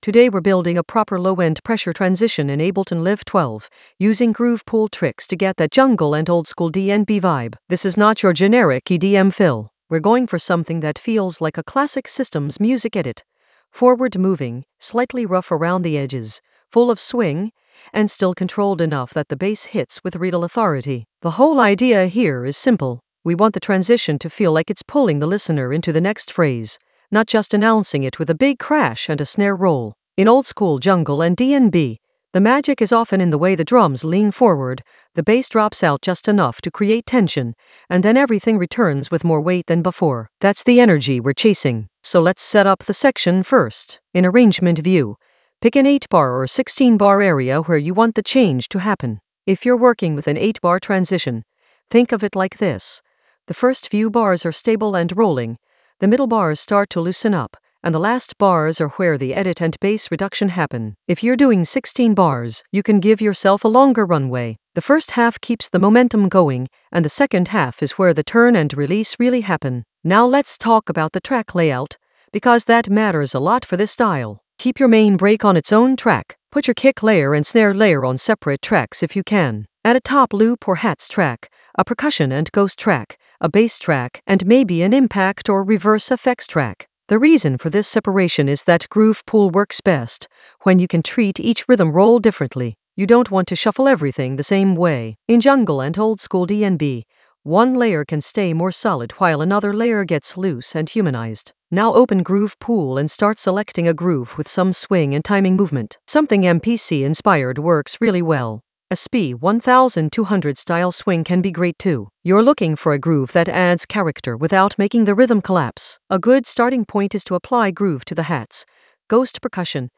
Narrated lesson audio
The voice track includes the tutorial plus extra teacher commentary.
An AI-generated advanced Ableton lesson focused on Low-End Pressure Ableton Live 12 transition approach using groove pool tricks for jungle oldskool DnB vibes in the Edits area of drum and bass production.